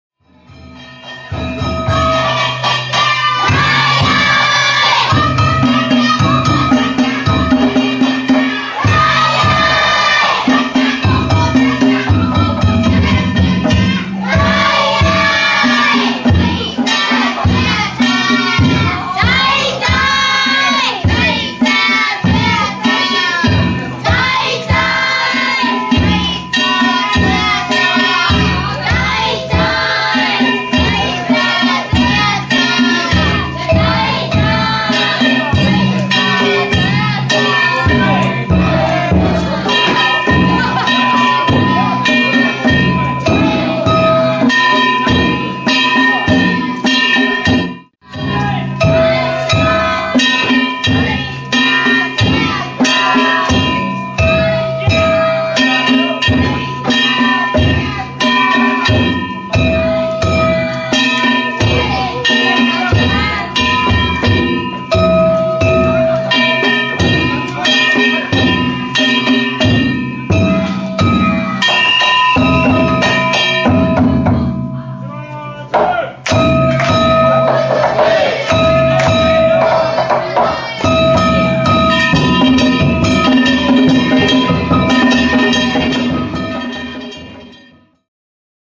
平成２９年７月２３日、東大阪市の稲田夏祭り地車曳行を見に行ってきました。
稲田八幡宮まで行くと神社裏手からお囃子が聞こえてきます。
曲がり角のお囃子です。